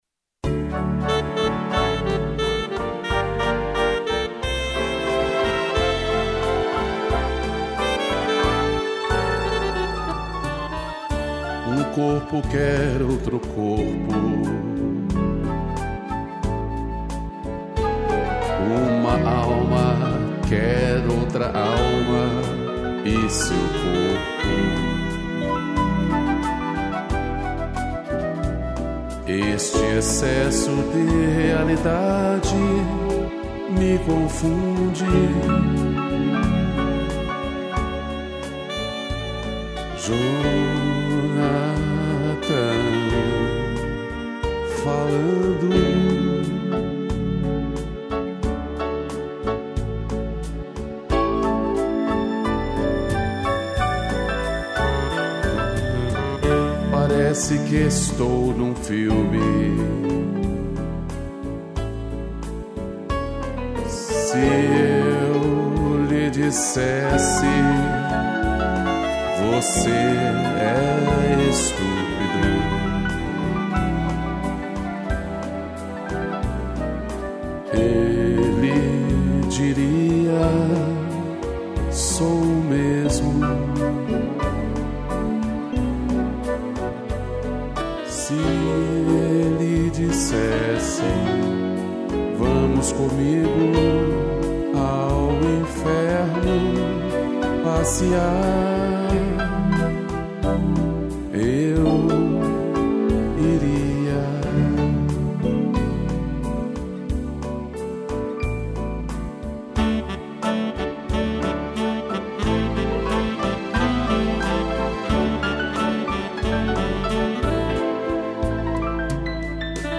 piano, sax, flauta e cello